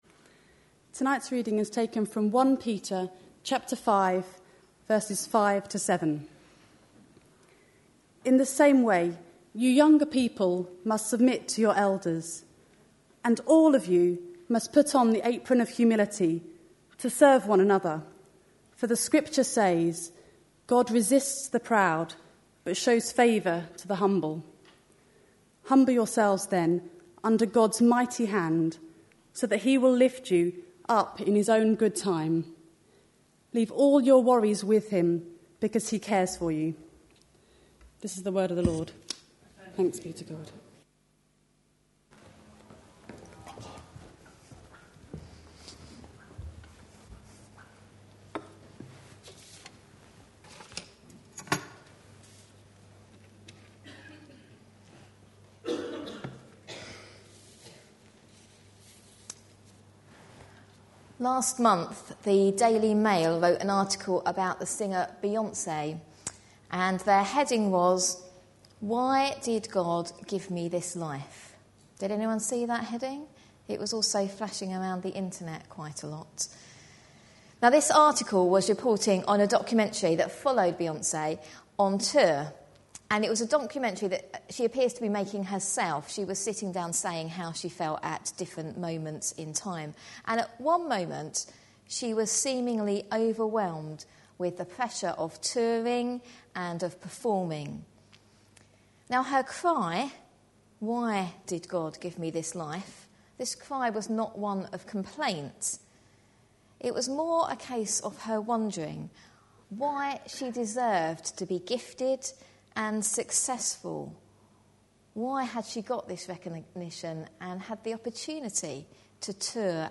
A sermon preached on 12th December, 2010, as part of our A Letter to Young Christians series.